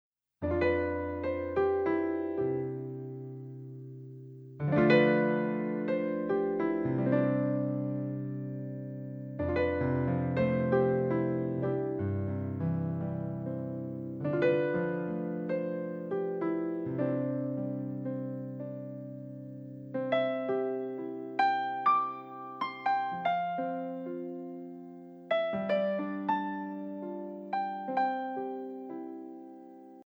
Mp3 Instrumental Song Download